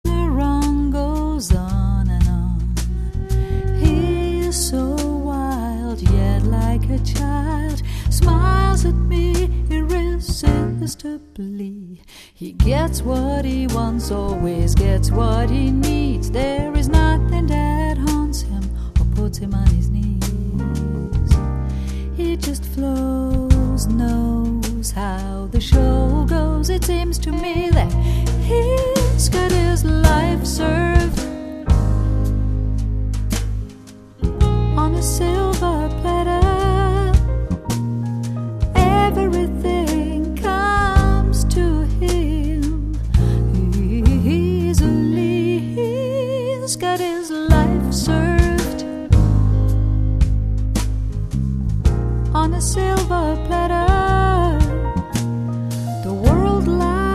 piano & keyboards
drums and percussion
saxophones and percussion
trumpet